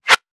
weapon_bullet_flyby_20.wav